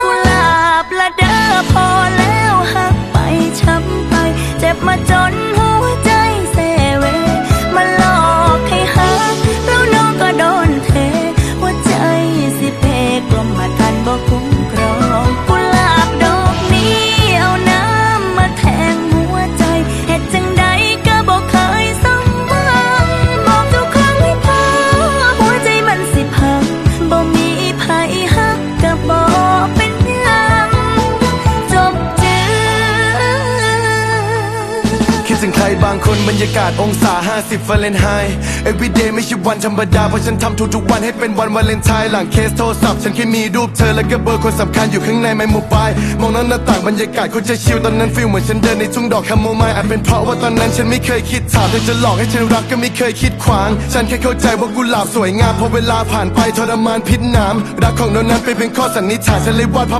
ภาพทดสอบสีแบบกว้างชัดๆ SMPTE Colour Bars HD (16:9) เสียงบีบ ToneBox 1 KHz.